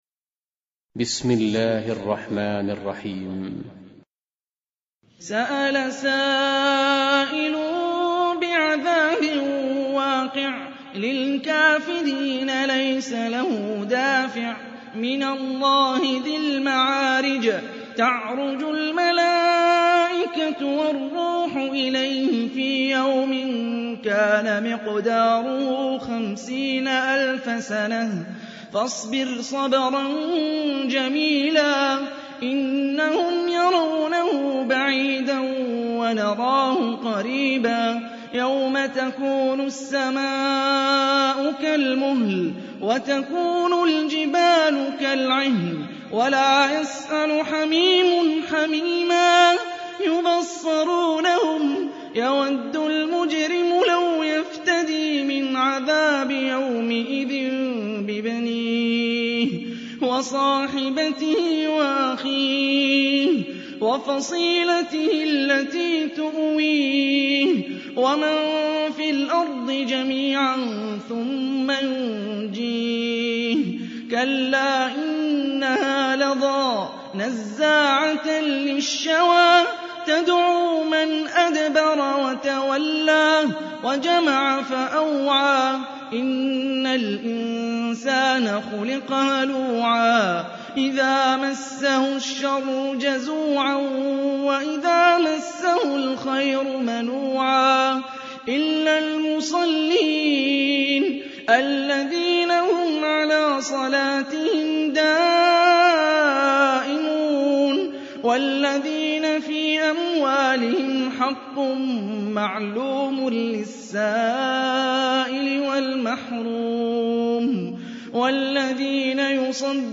Қуръони карим тиловати, Қорилар.